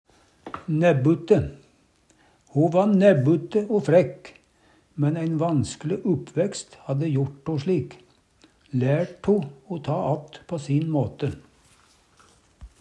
næbbute - Numedalsmål (en-US)